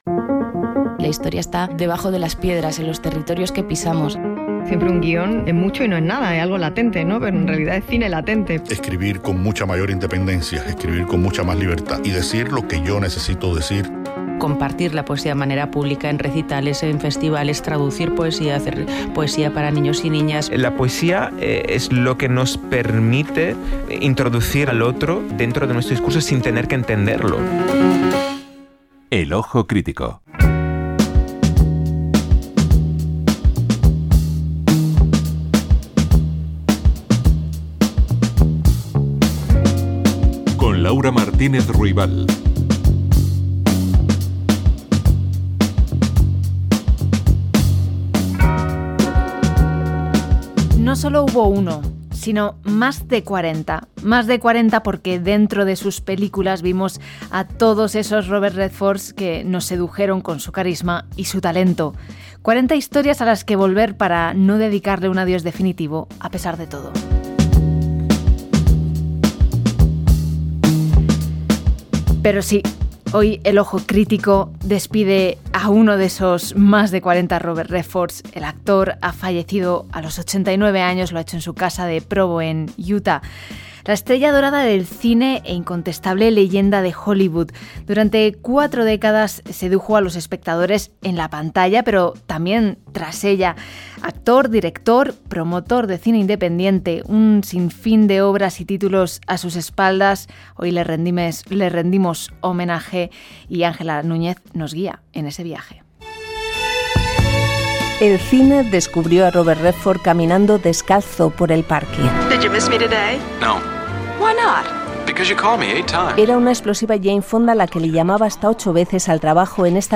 460a9dbf03dd4093edcc3bb43de981672a015521.mp3 Títol Radio 5 Emissora Radio 5 Barcelona Cadena RNE Titularitat Pública estatal Nom programa El ojo crítico Descripció Careta del programa, record a l'actor i director Robert Redford que havia mort aquell dia.
Gènere radiofònic Cultura